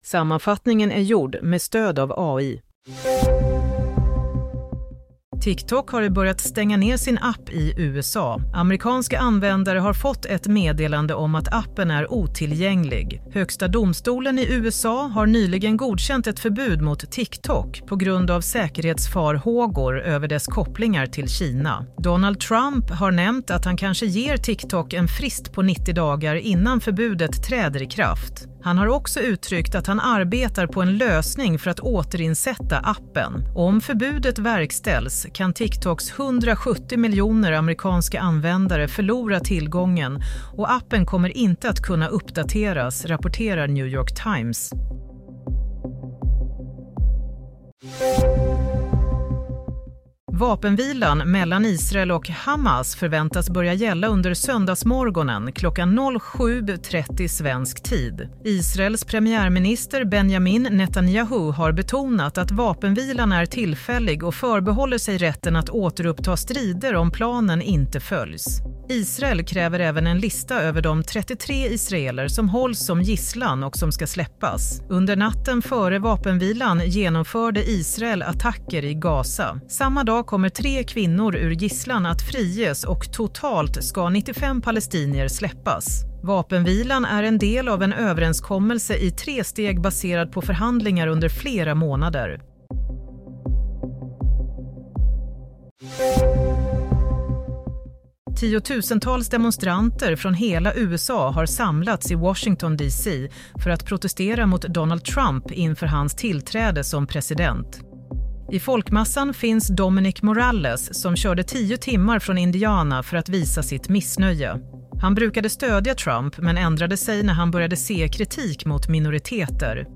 Nyhetssammanfattning - 19 januari 07:00